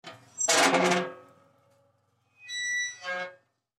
Звуки калитки
Скрип старой калитки при закрытии